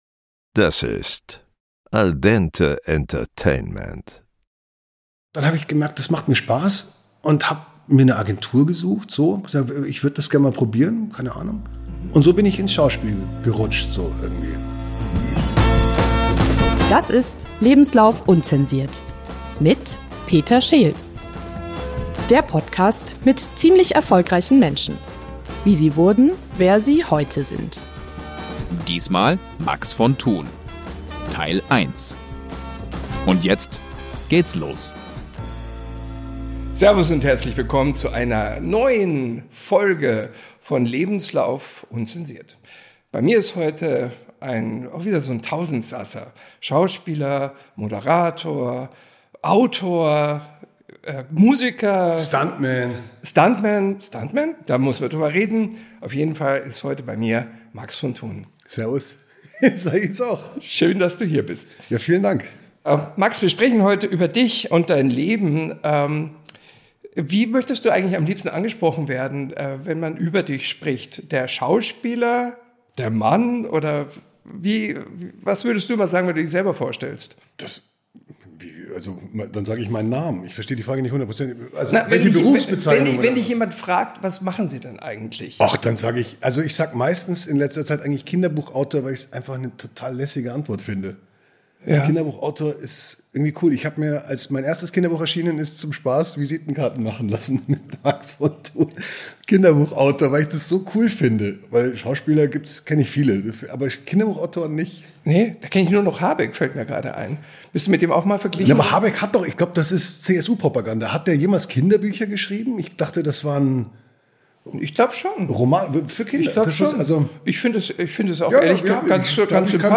Ein Gespräch voller Humor, Musikliebe und Ehrlichkeit.